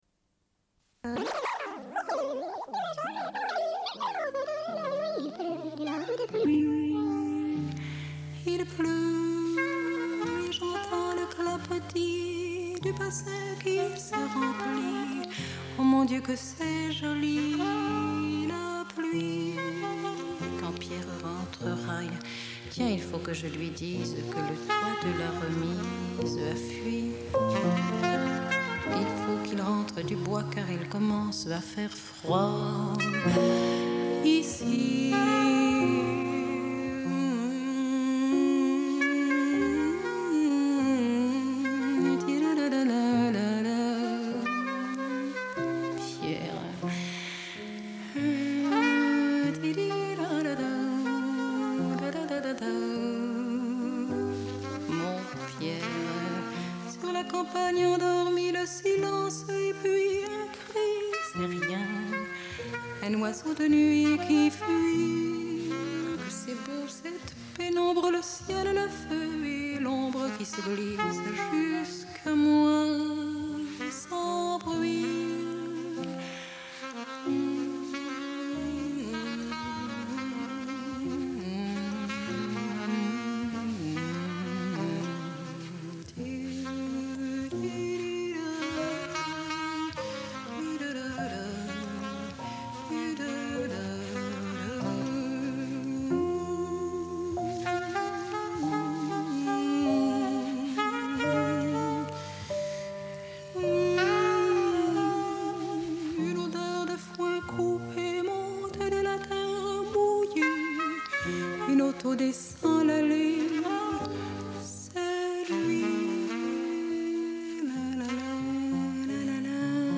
Une cassette audio, face A